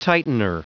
Prononciation du mot tightener en anglais (fichier audio)
Prononciation du mot : tightener